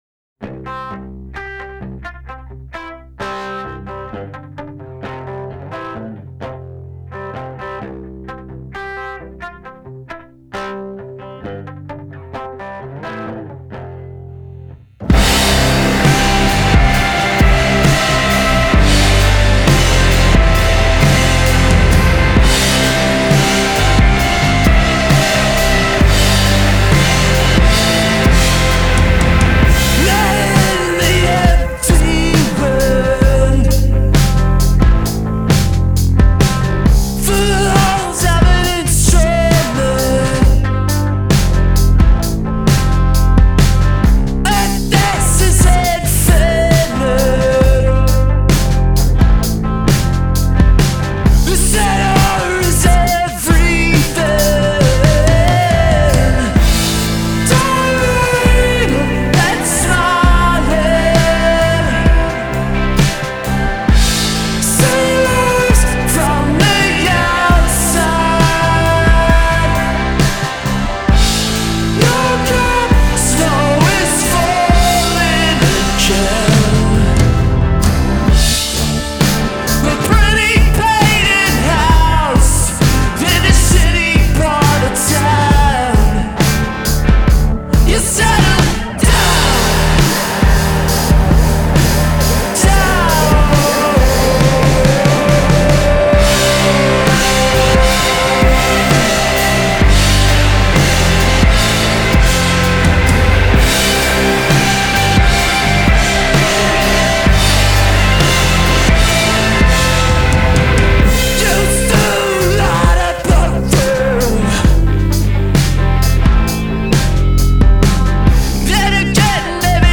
But they mix it with bigger riffs
veer dangerously close to arena rock